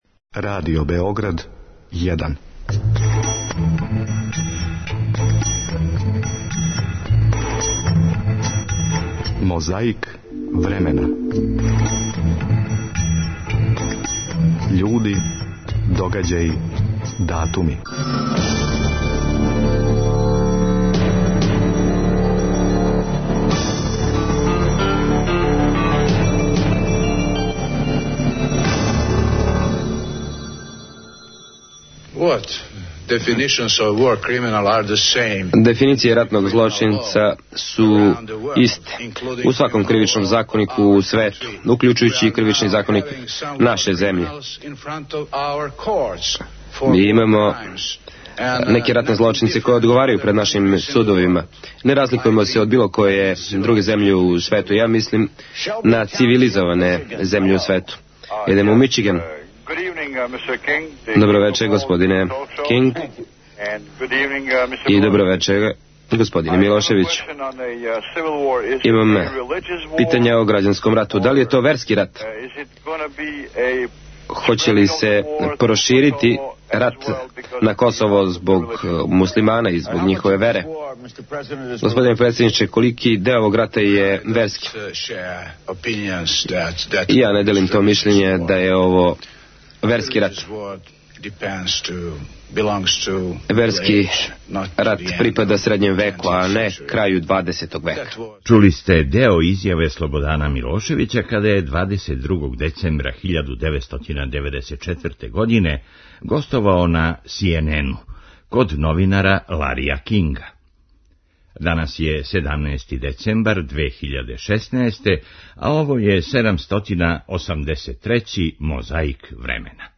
Фронт код Окучана. Екипа Радио Новог Сада и ратни извештач интервјуишу борце.